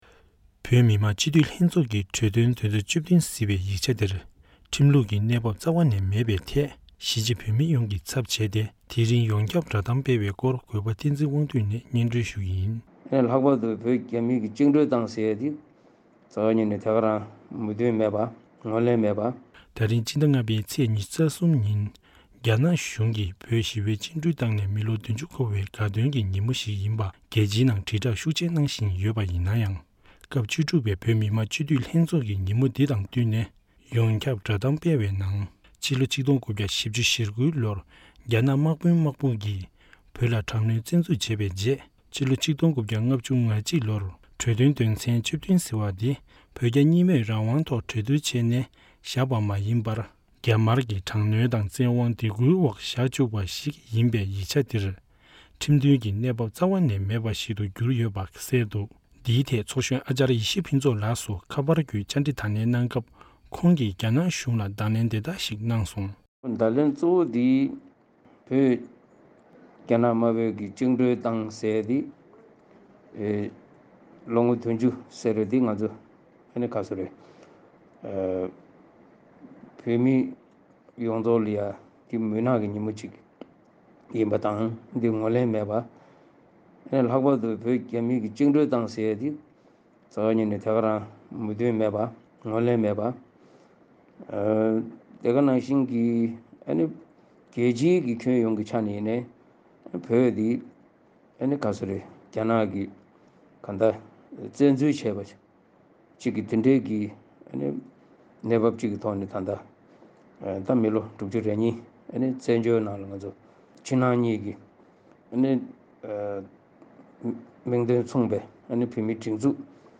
ཡང་ཚོགས་གཞོན་ཨཱཅརྱ་ཡེ་ཤེས་ཕུན་ཚོགས་ལགས་སུ་ཞལ་པར་བརྒྱུད་བཅའ་འདྲི་ཞུ་སྐབས་ཁོང་གིས་བོད་རྒྱ་མིས་བཅིངས་འགྲོལ་བཏང་ནས་ལོ་ངོ་༧༠ཟེར་བ་འདི་བོད་མི་ཡོངས་ལ་མུན་ནག་གི་ཉིན་མོ་ཞིག་ཡིན་པ་དང་། ལྷག་པར་དུ་རྒྱ་གཞུང་གིས་བོད་བཅིངས་འགྲོལ་བཏང་ཟེར་བ་འདི་རྩ་བ་ཉིད་ནས་མོས་མཐུན་དང་ངོས་ལེན་མེད་པ་མ་ཟད། རྒྱལ་སྤྱིའི་ནང་དུ་ཡང་བོད་འདི་རྒྱ་ནག་གིས་བཙན་འཛུལ་བྱས་པའི་གནང་བབ་ཅིག་གི་ཐོག་ནས་མི་ལོ་༦༢བཙན་བྱོལ་ནང་༧གོང་ས་མཆོག་གིས་ཆབ་སྲིད་ལམ་སྟོན་འོག་གནས་པའི་ཕྱི་ནང་གཉིས་ཀྱི་མིང་དོན་མཚུངས་པའི་བོད་མིའི་སྒྲིག་འཛུགས་ཡོད་པ་ཡིན་ཙང་། དེའི་རྒྱུ་མཚན་གྱིས་རྒྱ་གཞུང་གི་འཁྲབ་སྟོན་ག་ཚོད་བྱས་ཀྱང་། རྒྱལ་སྤྱིའི་ནང་ངོས་ལེན་བྱེད་ཀྱི་མེད་པ་སོགས་ཡོངས་ཁྱབ་གསལ་བསྒྲགས་སྤེལ་བ་ཁོང་གིས་ངོ་སྤྲོད་གནང་བྱུང་།